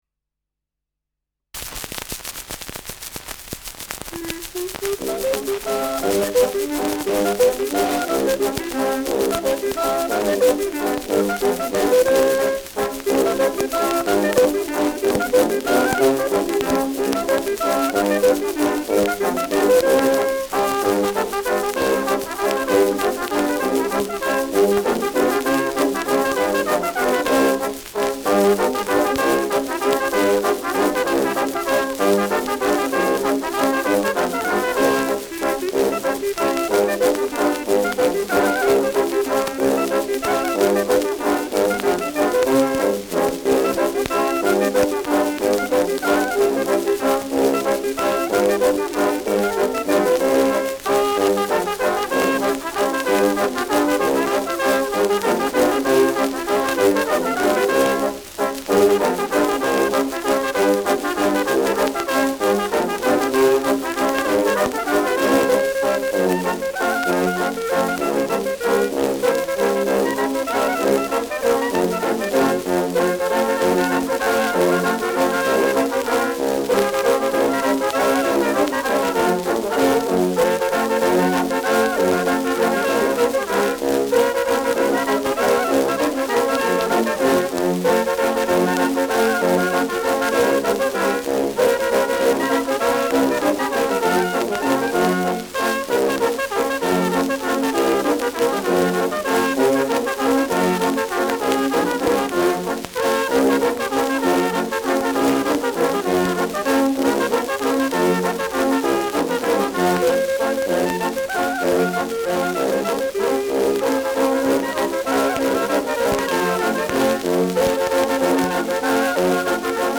Schellackplatte
leichtes Rauschen : Knistern